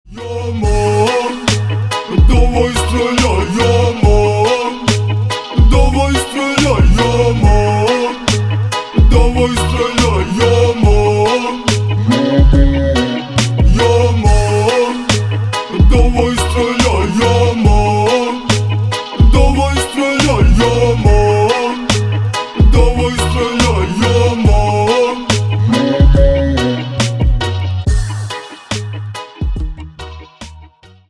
на русском гангстерские